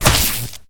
flesh3.ogg